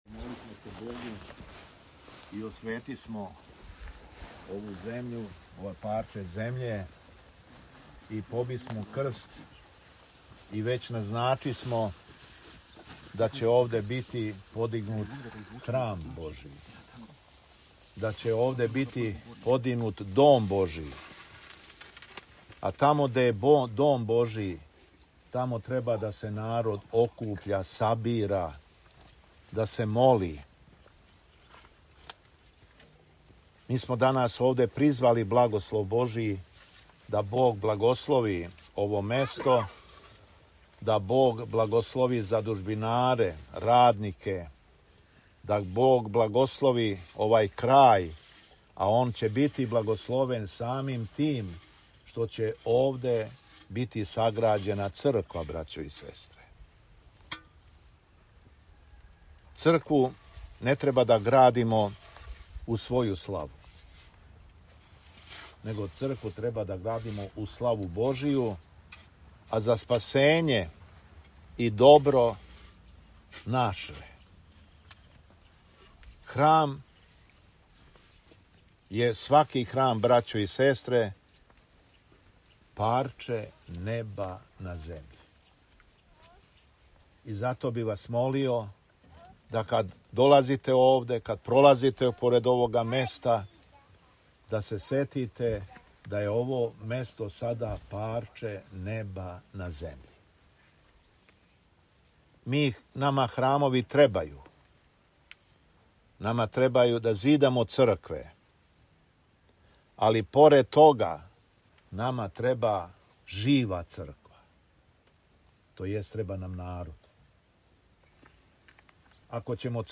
Након освећења, Митрополит се обратио верном народу беседом:
Беседа Његовог Високопреосвештенства Митрополита шумадијског г. Јована